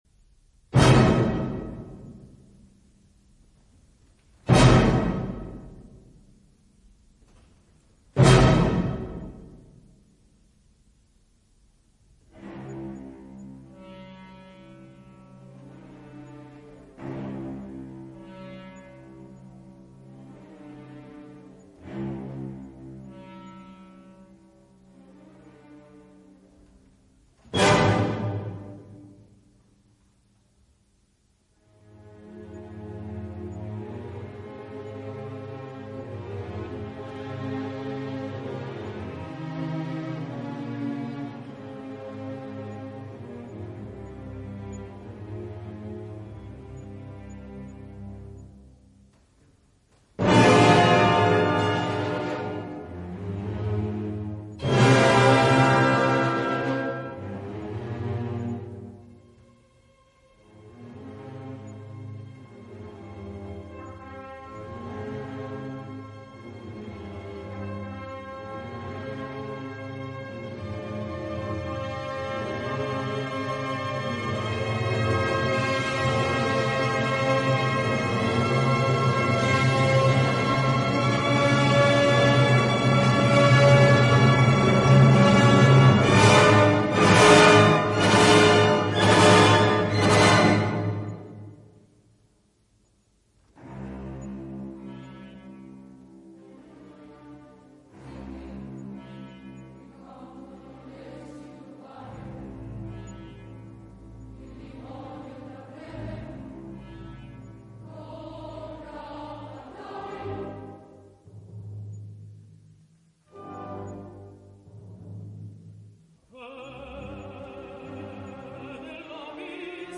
opera completa, registrazione in studio.
Coro, Ulrica, Riccardo, Silvano, Servo